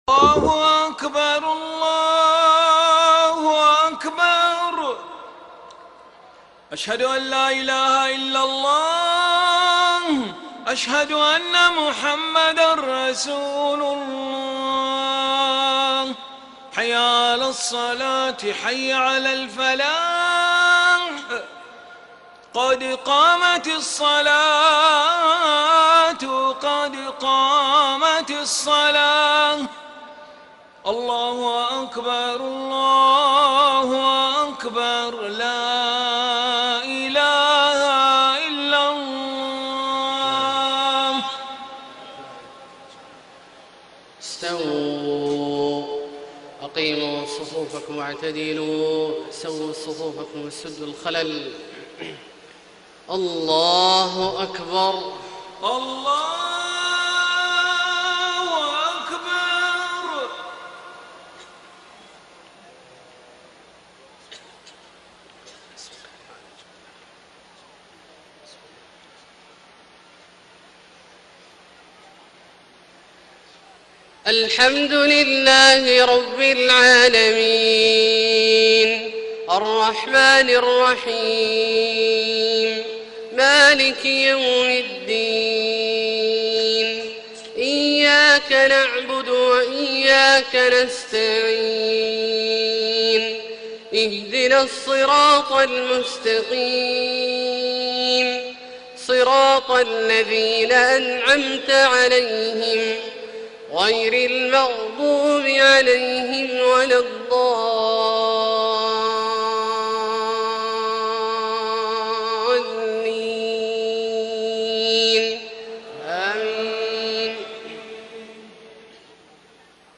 صلاة العشاء 9 ذوالحجة 1433هـ من سورة الحج{26-37} > ١٤٣٣ هـ > الفروض - تلاوات عبدالله الجهني